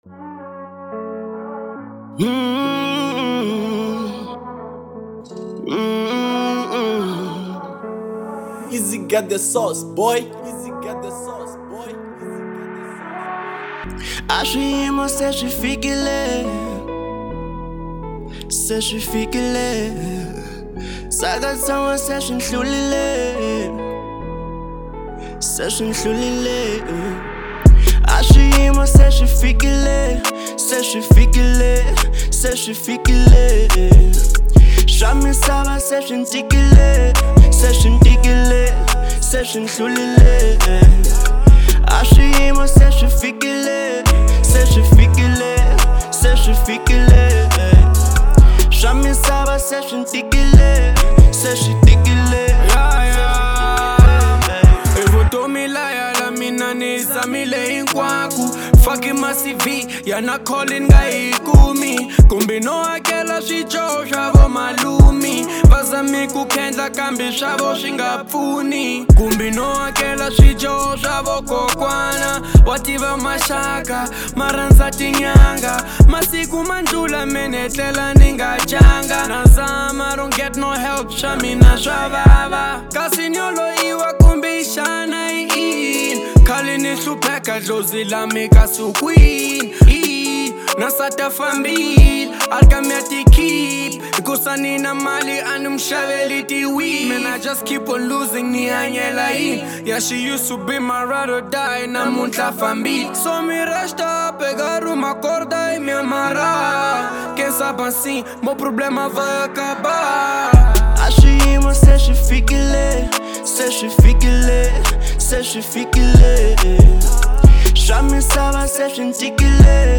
03:13 Genre : Hip Hop Size